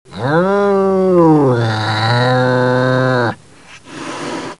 catgrowl.mp3